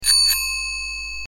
Fahrradklingel Vision ellipse
Lautstärke: 91.00 db
Ton: cis
Der wohltönende, tiefe Klang passt perfekt zum edlen Design.
Fahrradklingel_Vision_Ellipse_T.mp3